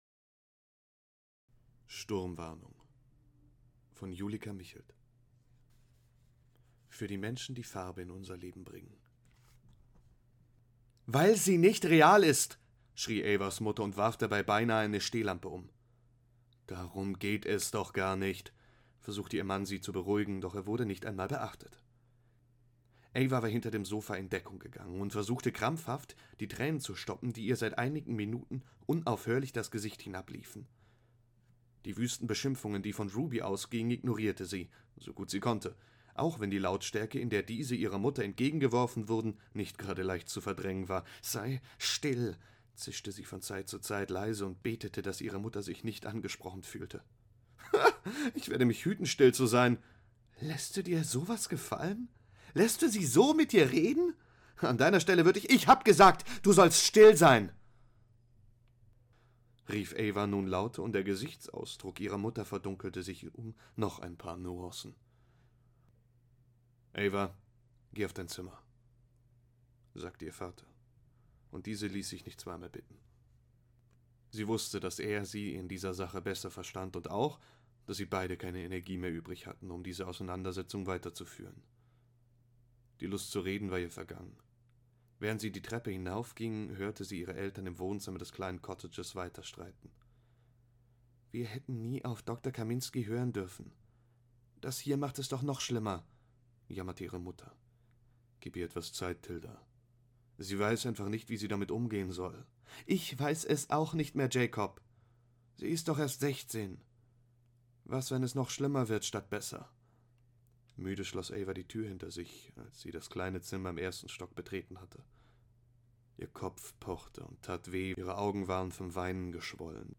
Mittel minus (25-45)
Commercial (Werbung)